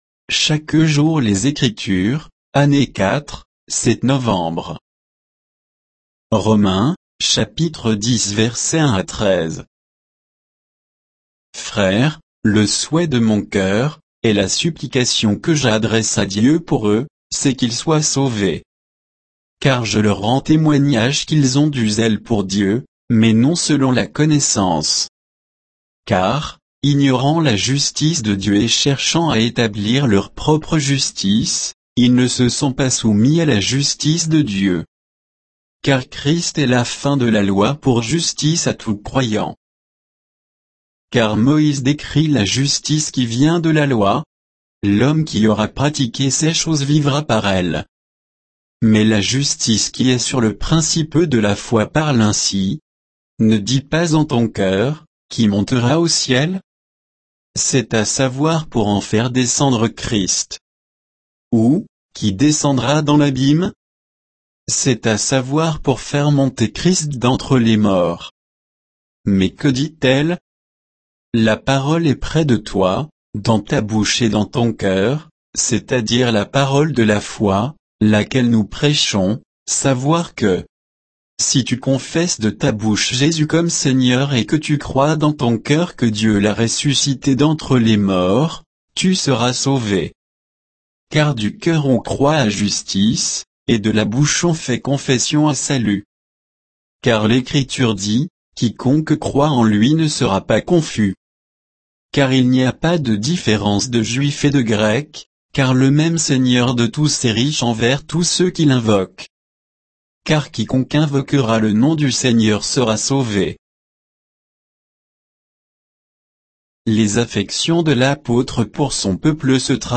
Méditation quoditienne de Chaque jour les Écritures sur Romains 10